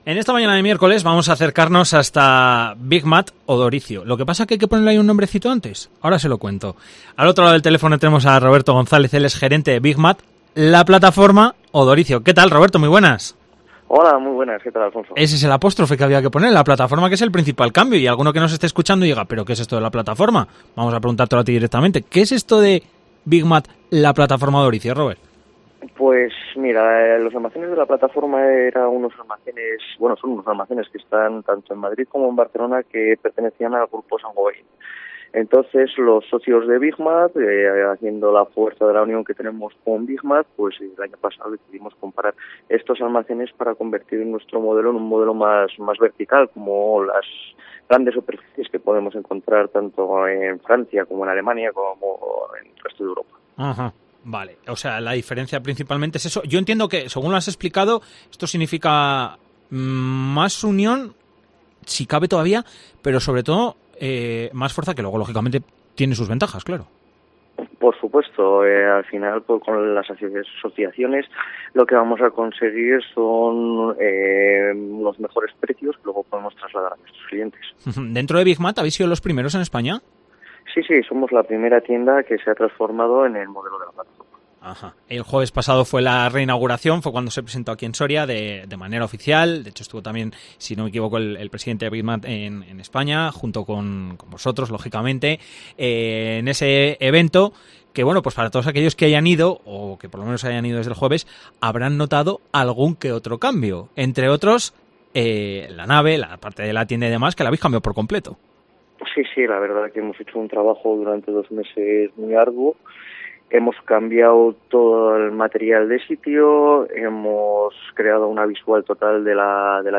Entrevista: BigMat La Plataforma Odoricio Soria.